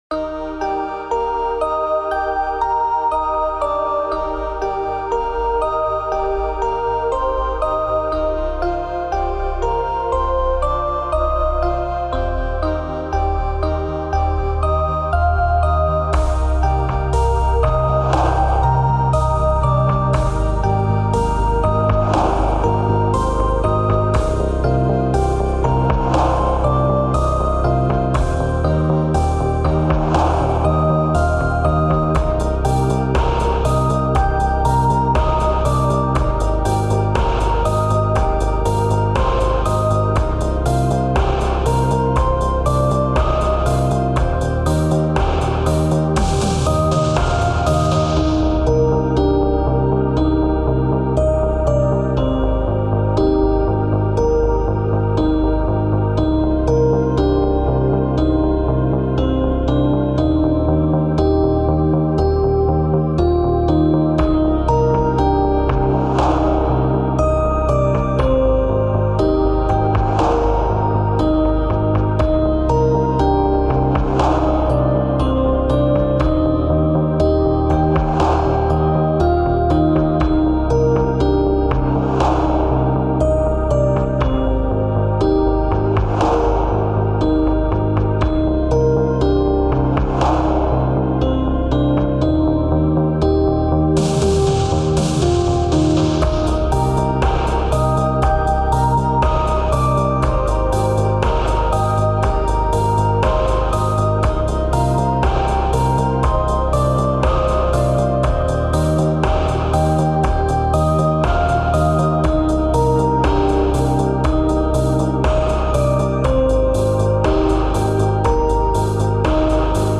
I tried to make Chillwave
Music / 80s
A very relaxing tune.